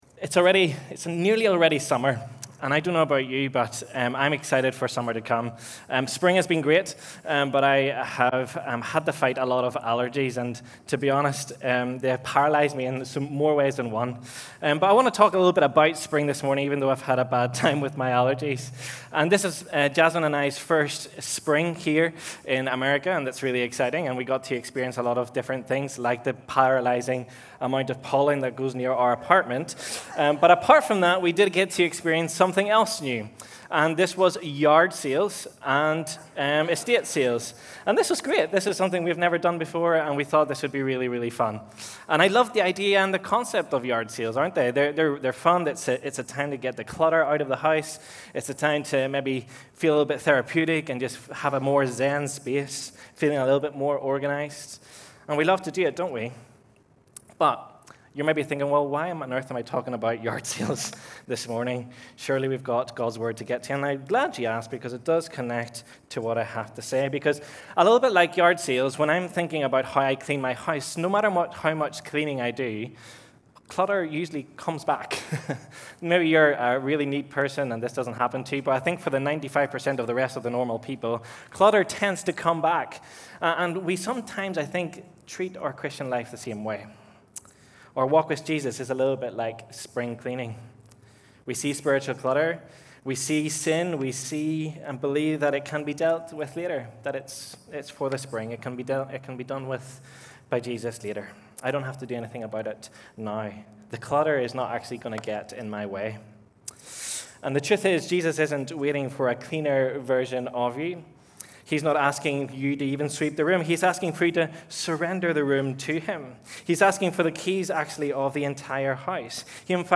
Messages from Grace Community Church in Kingsville, MD